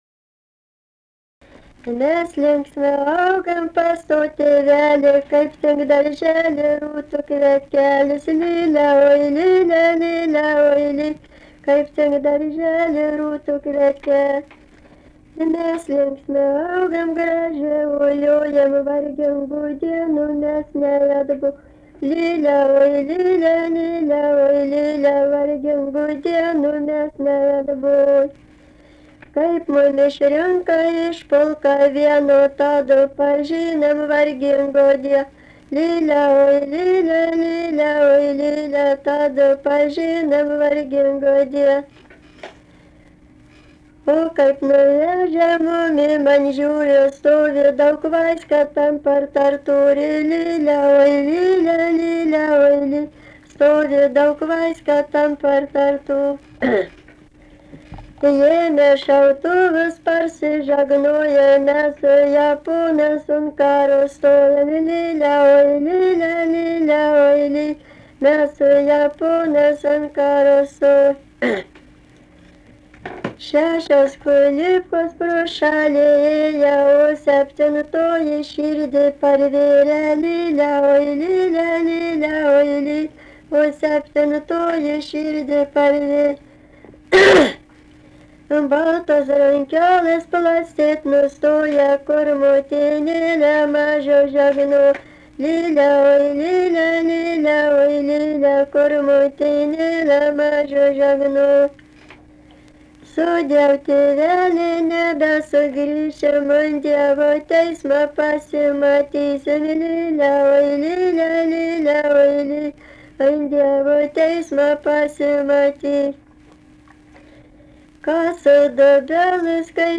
daina, karinė-istorinė
Mantvydai
vokalinis